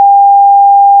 Sonido idéntico al anterior pero con mucha intensidad.
Escucha aquí el mismo sonido con mucha intensidad.